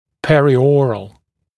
[ˌperɪ’ɔːrəl][ˌпэри’о:рэл]периоральный, околоротовой